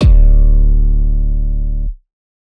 Metro 808s [Trunk].wav